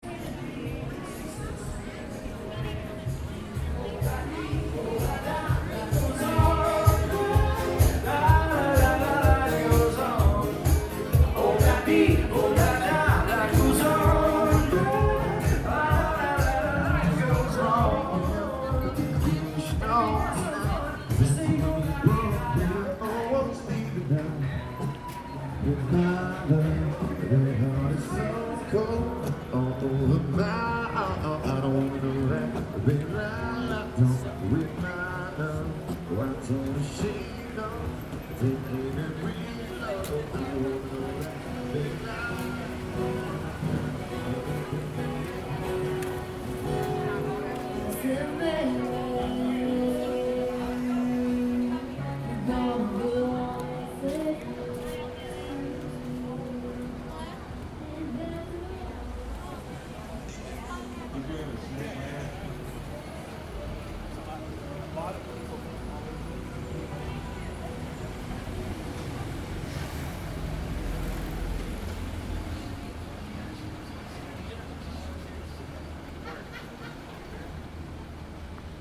Sur Lower Broadway, les honky tonk bars se succèdent pour le plaisir des touristes. L’omniprésence de la musique y est telle qu’à plusieurs endroits de cette avenue, de petits haut-parleurs incrustés dans des bornes électriques diffusent la radio en continu !
Le son de Lower Broadway, à midi :